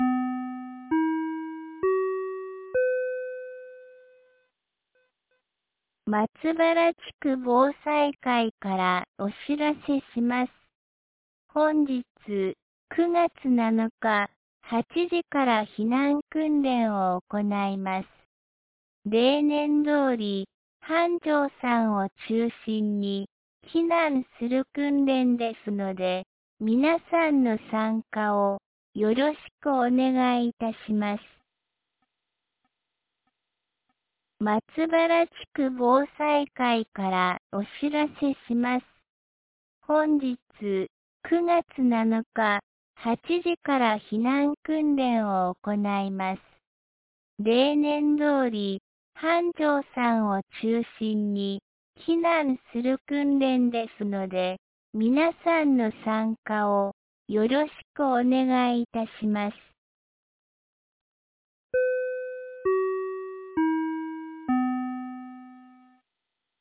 2025年09月07日 08時01分に、安芸市より井ノ口へ放送がありました。